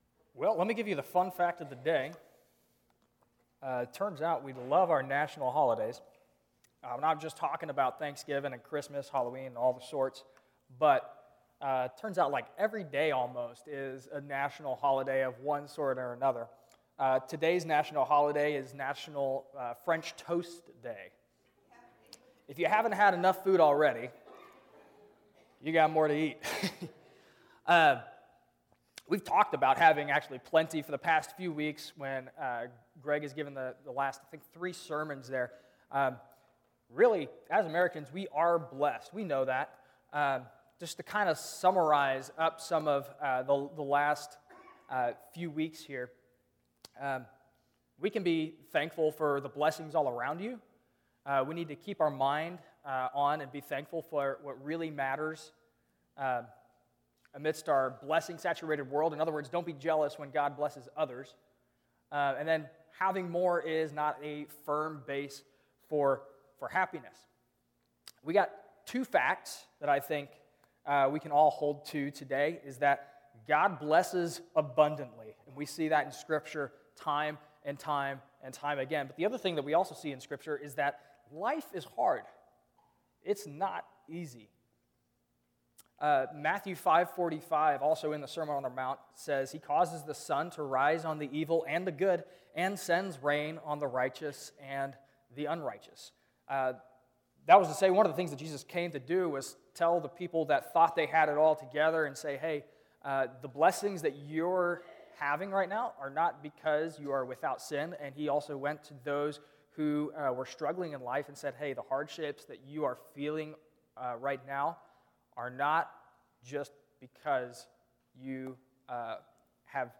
Sermon: Gratitude – Rockville Christian Church